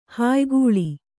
♪ hāygūḷi